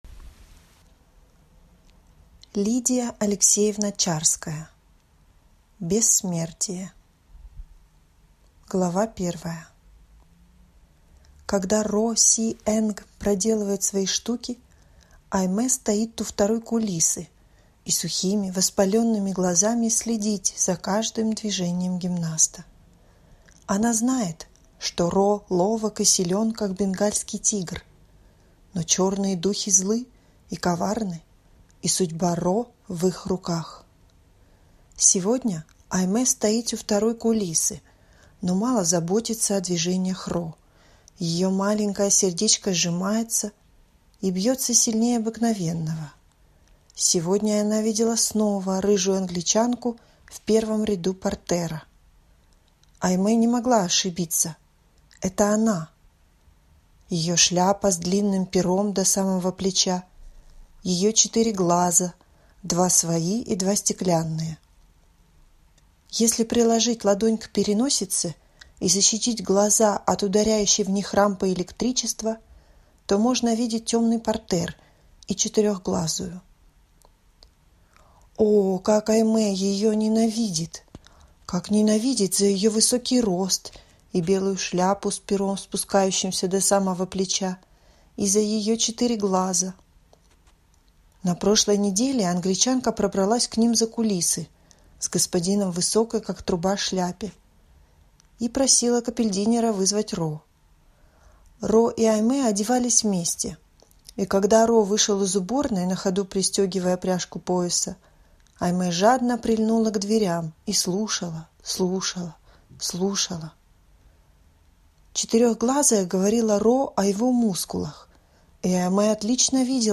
Аудиокнига Бессмертие | Библиотека аудиокниг
Прослушать и бесплатно скачать фрагмент аудиокниги